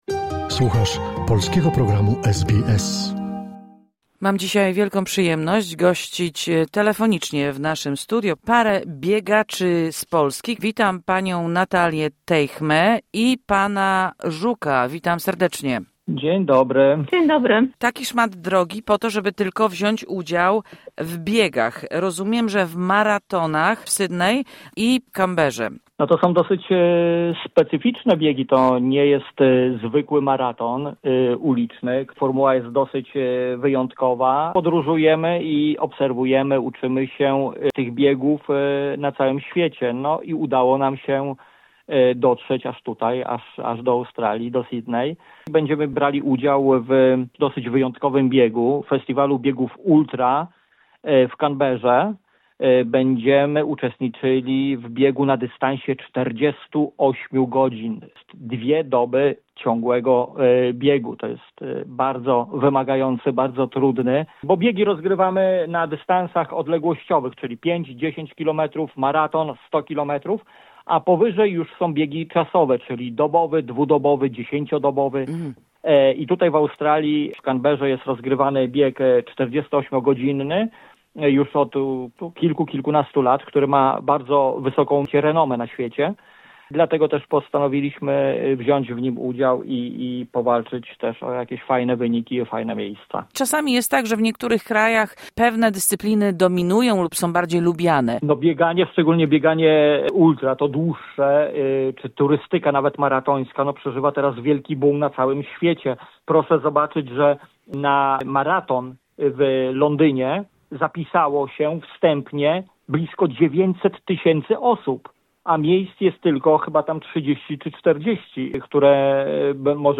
Rozmowa z polskimi maratończykami, którzy biorą udział w maratonach w Australii.